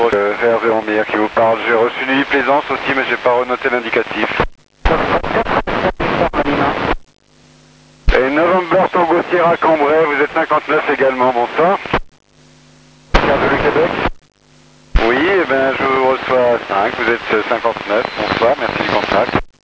QSO PHONIE avec MIR